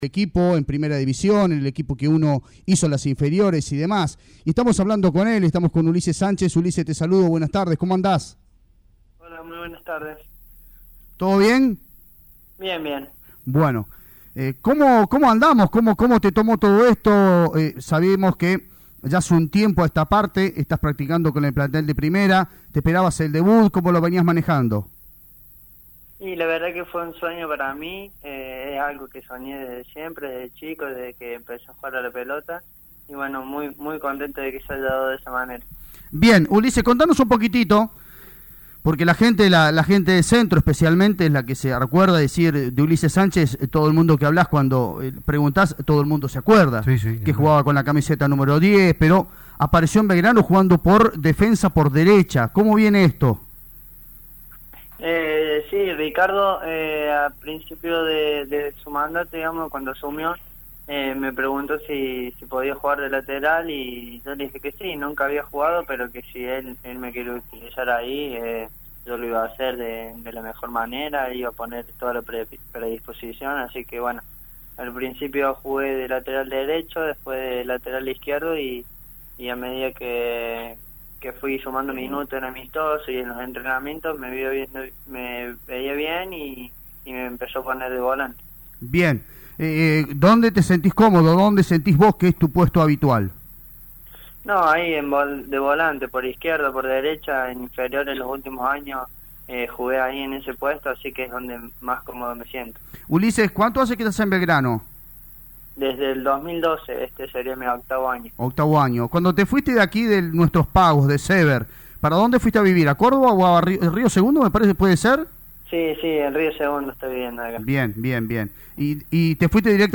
dialogó con LA RADIO 102.9 en LA RADIO DEPORTIVA.